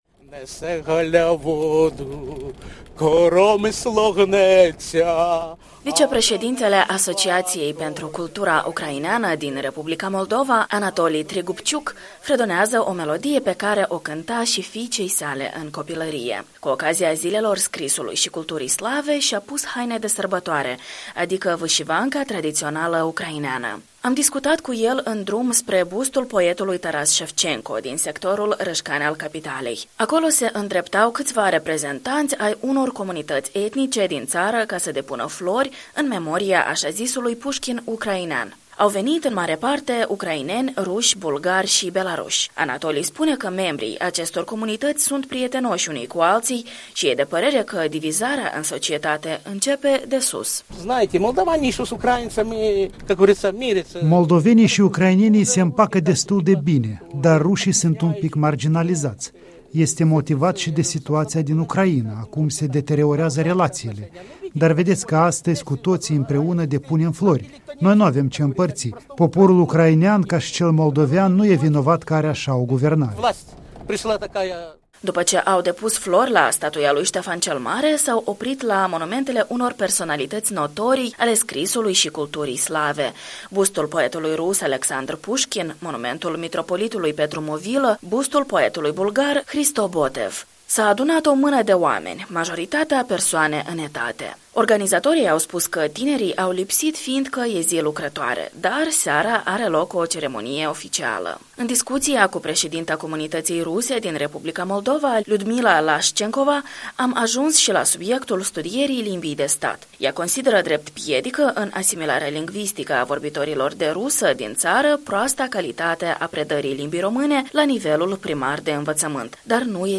a luat parte la ceremonia de deschidere.